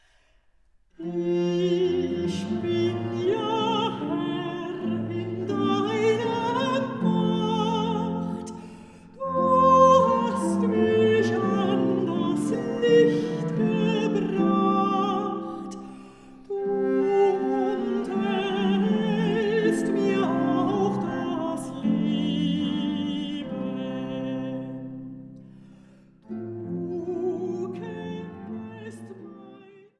Mezzosopran
Viola da Gamba
Harfe